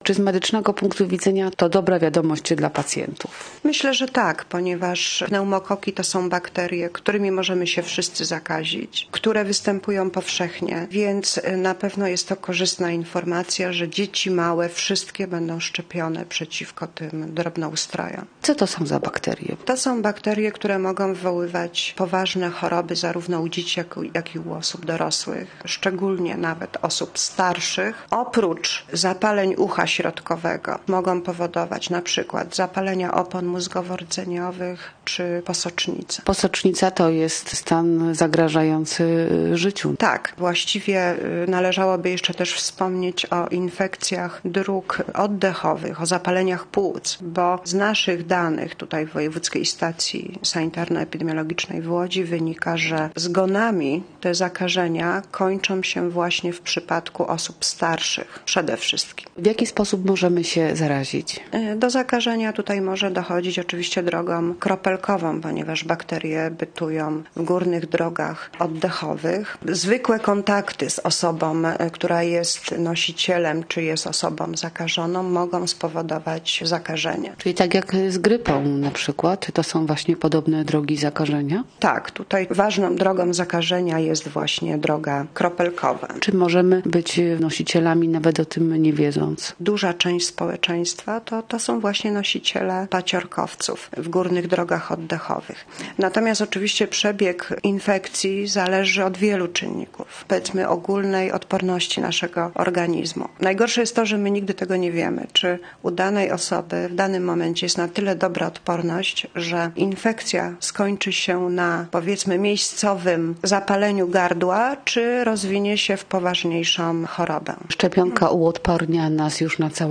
Posłuchaj rozmowy: Nazwa Plik Autor Szczepienia przeciw pneumokokom audio (m4a) audio (oga) Od 1 stycznia 2017 r. będą szczepione wszystkie nowo narodzone dzieci.